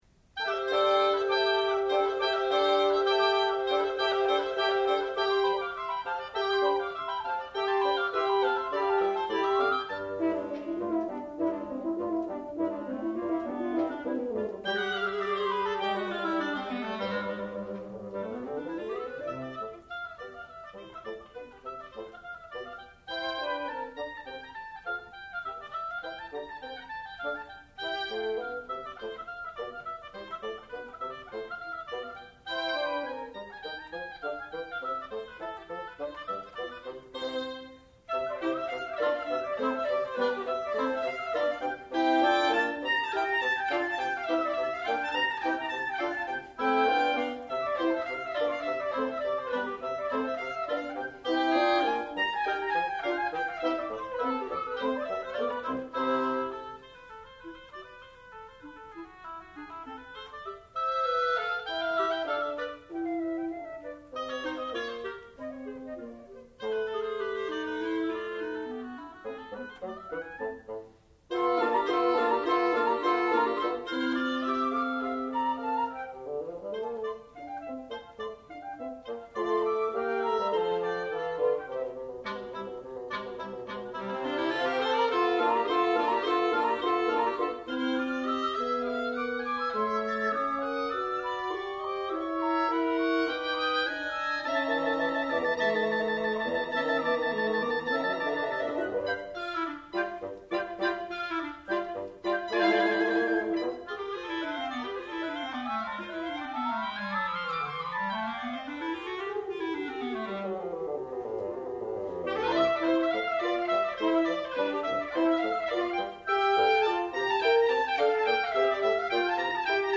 sound recording-musical
classical music
bassoon
horn
flute